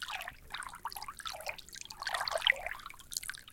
Splash_water02.ogg